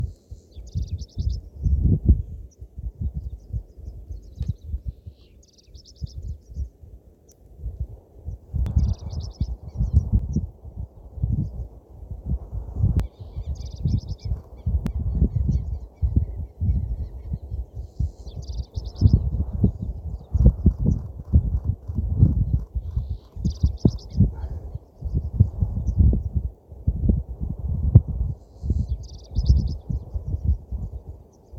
Short-billed Pipit (Anthus furcatus)
Location or protected area: Estancia Nahuel Ruca
Condition: Wild
Certainty: Photographed, Recorded vocal